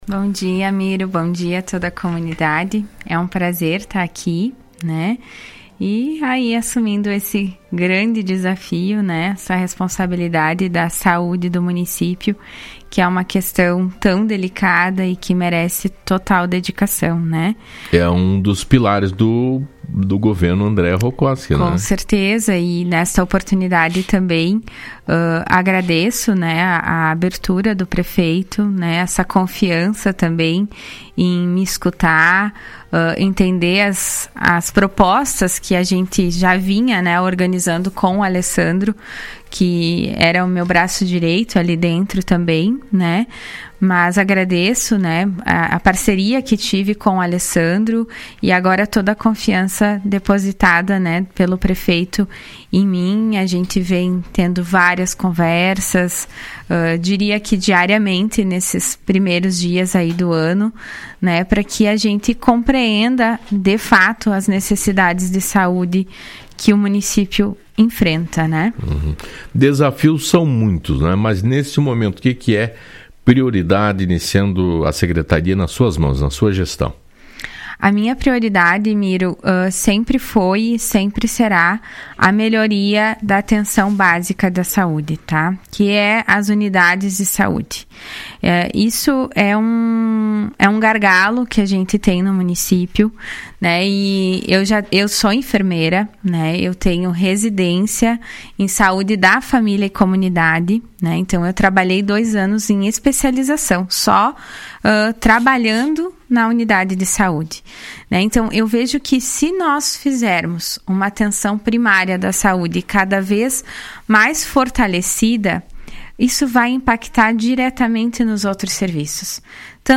A secretária municipal da saúde, Aline Soares Salvador participou na manhã desta quarta-feira, dia 21 do programa Fala Cidade, após ter assumido a pasta em dezembro de 2025.
Aline detalhou na entrevista o trabalho que será desenvolvido a partir dos próximos meses aos pacientes nos postos dos bairros, afirmando que é preciso foco na atenção básica de saúde.